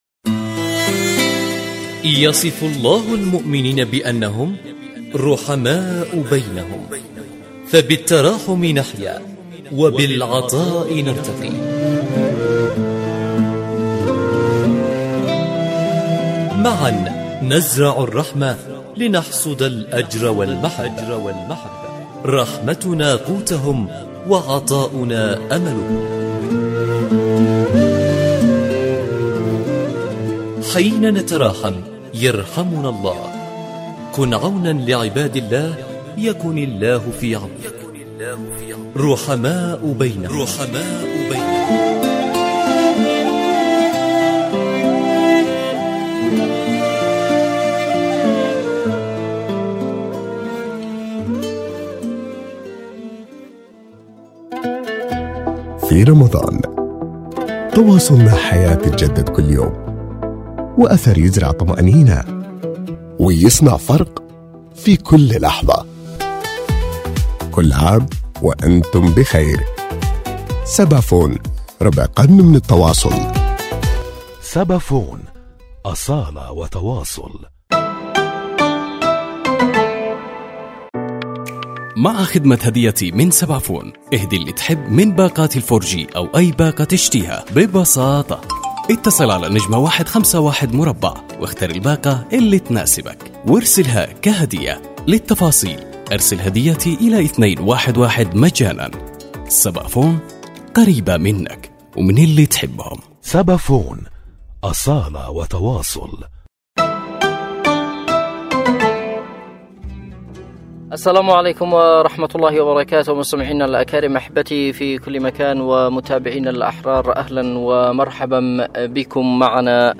رحماء بينهم، برنامج إذاعي يأخذكم في رحلة إذاعية قصيرة ، نستكشف خلالها العديد من الحالات الإنسانية التي تحتاج الى مد يد العون في شهر الخيرات، ويسلط الضوء على حالات إنسانية.